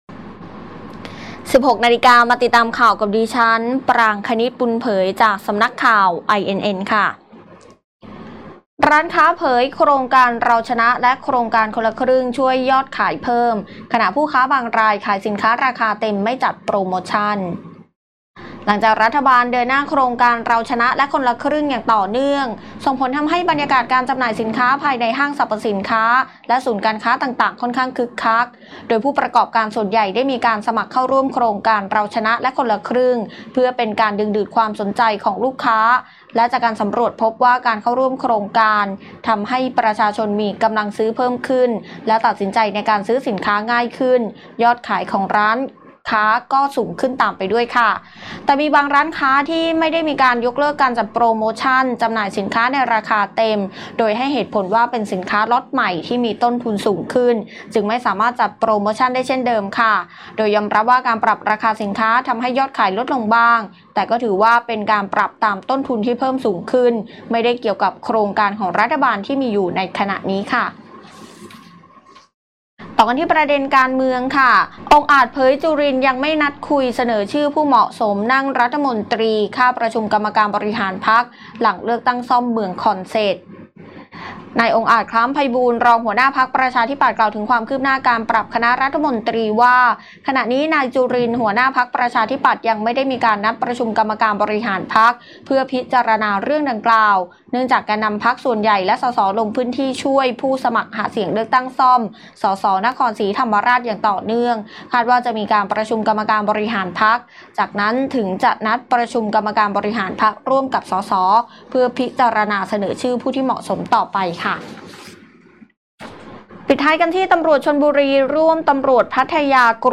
ข่าวต้นชั่วโมง 16.00 น.